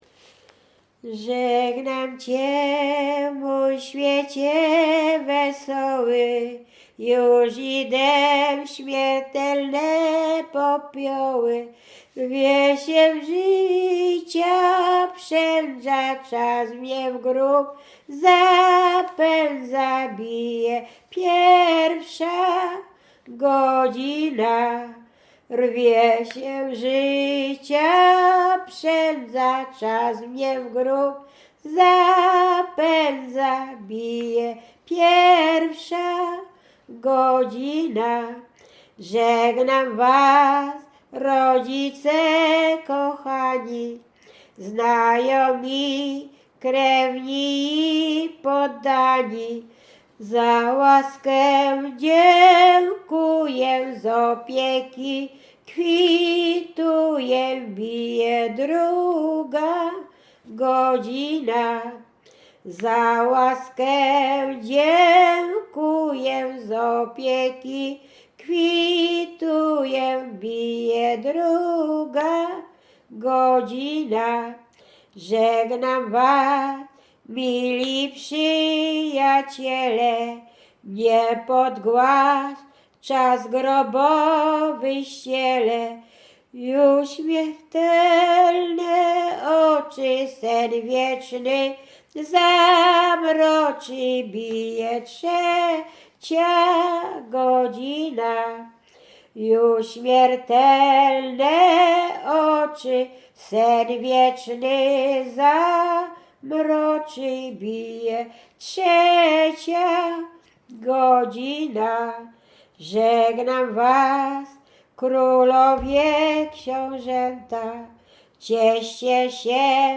Łęczyckie
województwo łódzkie, powiat łódzki, gmina Zgierz, wieś Jasionka
Pogrzebowa
pogrzebowe nabożne katolickie do grobu